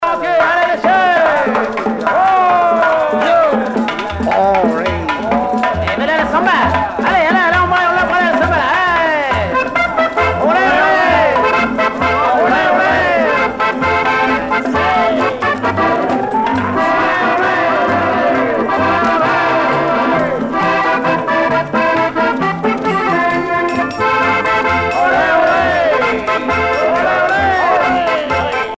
danse : samba
Pièce musicale éditée